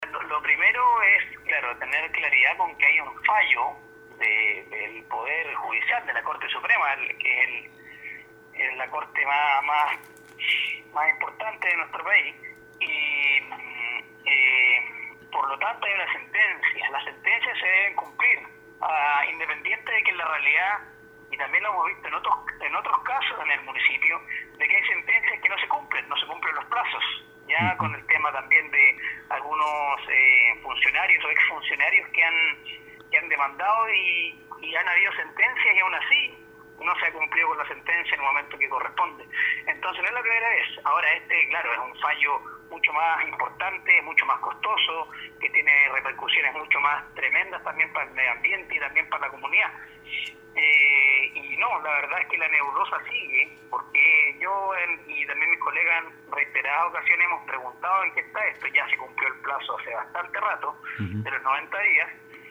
Así lo señaló el concejal Samuel Mandiola, quien acusó que el consistorio local no ha entregado de manera transparente el procedimiento que está llevando a cabo en torno al manejo y disposición de los residuos sólidos domiciliarios.